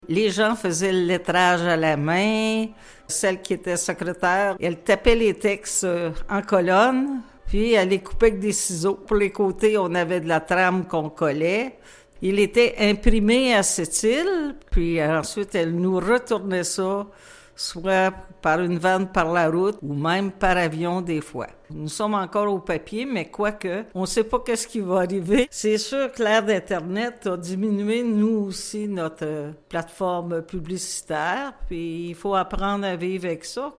En entrevue à la radio CFMF